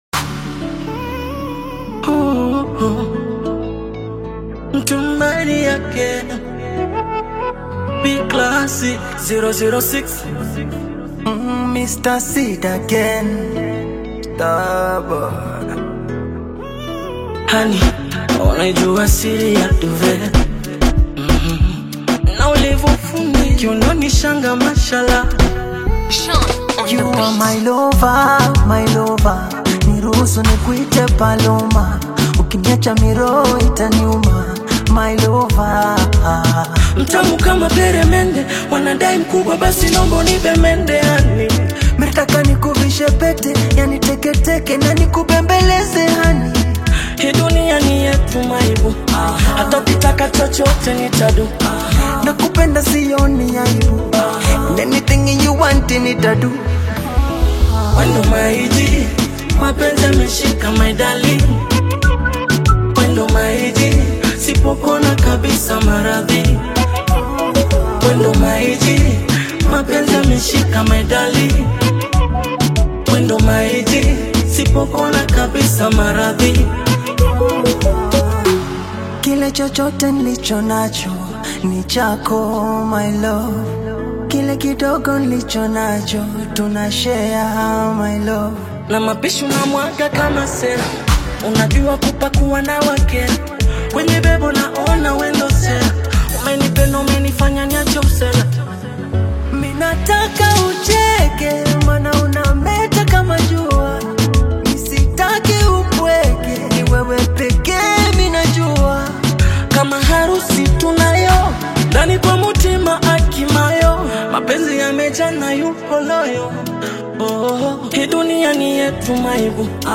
smooth Afro-Pop/Afro-fusion collaboration
silky vocals
expressive delivery